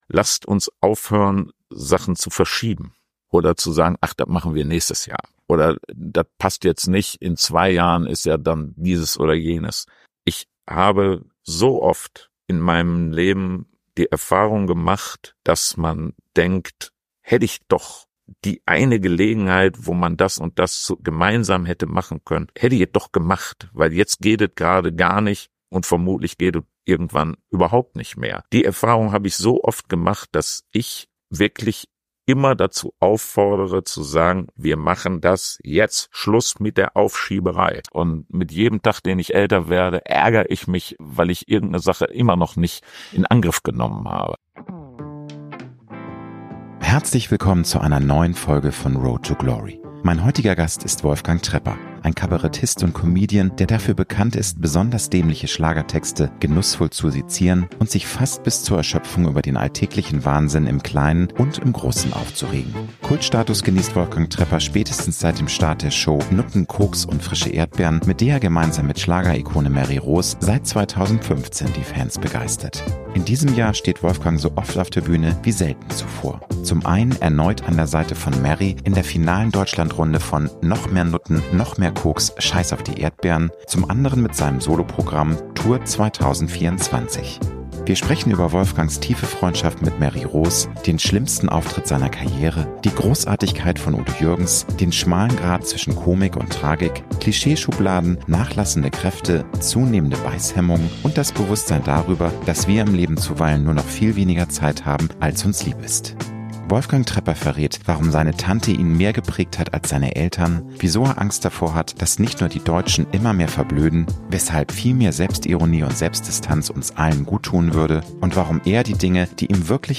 Mein heutiger Gast ist Wolfgang Trepper. Ein Kabarettist und Comedian, der dafür bekannt ist, besonders dämliche Schlagertexte genussvoll zu sezieren und sich fast bis zur Erschöpfung über den alltäglichen Wahnsinn im Kleinen und im Großen aufzuregen.